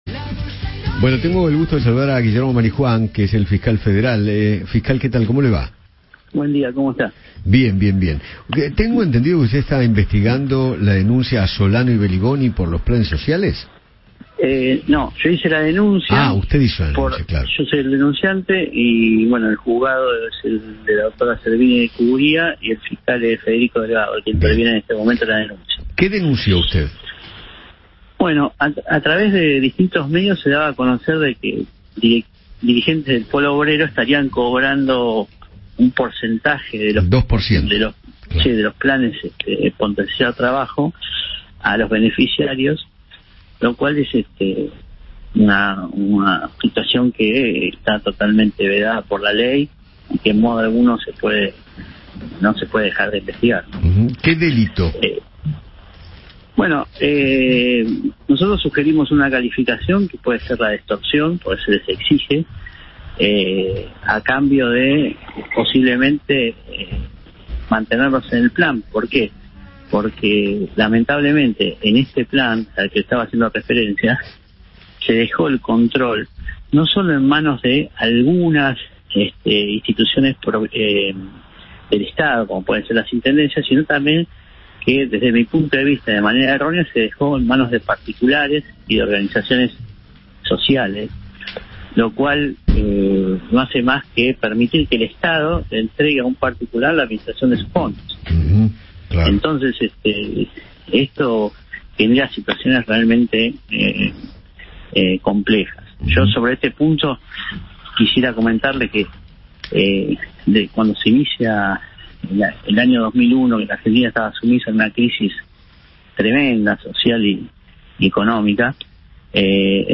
El fiscal Guillermo Marijuan dialogó con Eduardo Feinmann acerca de la denuncia que realizó contra los dirigentes del Polo Obrero, Gabriel Solano y Eduardo Belliboni, por extorsión.